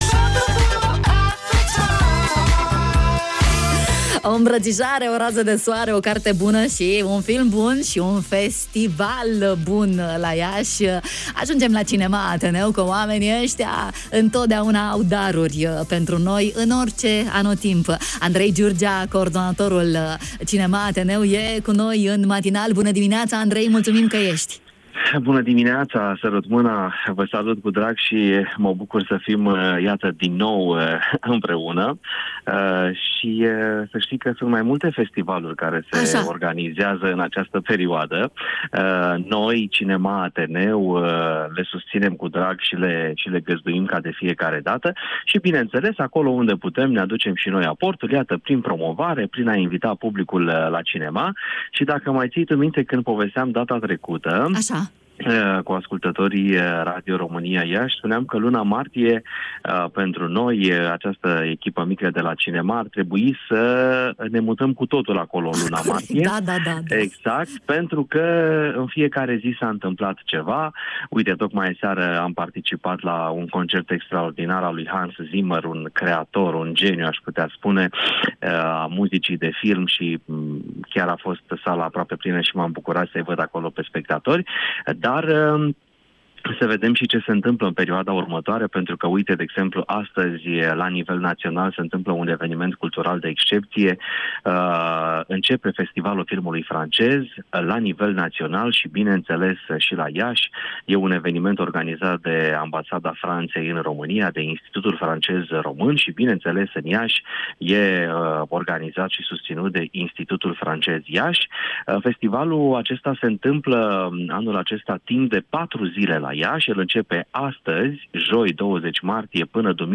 Festivaluri de Film la Cinema Ateneu.